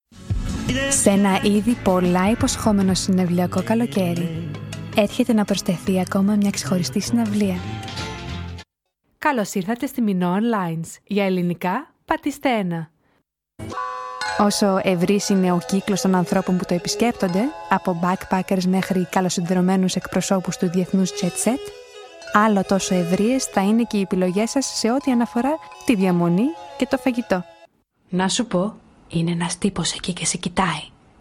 Greek, Female, 20s-30s